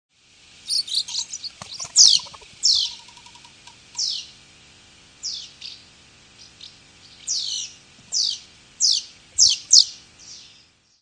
Jaskółka oknówka - Delichon urbicum